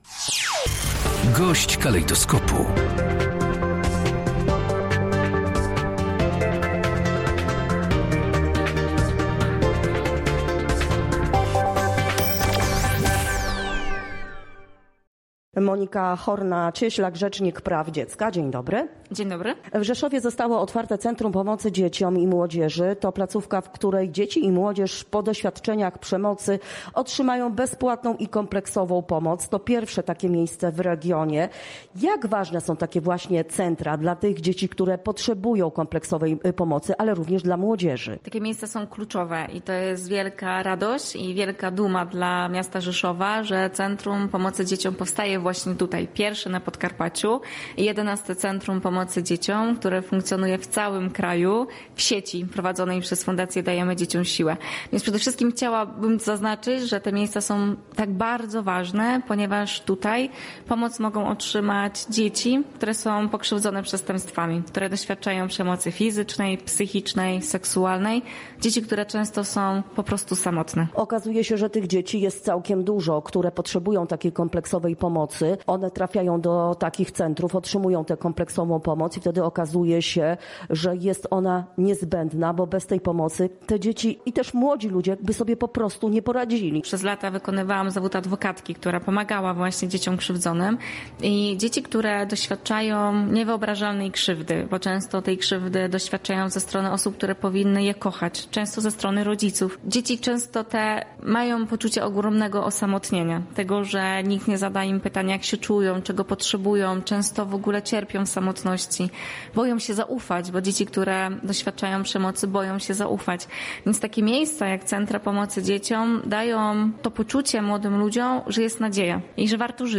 Monika Horna-Cieślak, Rzecznik Praw Dziecka, podkreśla, że to bardzo ważna inicjatywa.
Gość Radia Rzeszów zaznacza, że taka kompleksowa pomoc specjalistyczna pokrzywdzonym dzieciom i młodzieży jest bardzo istotna.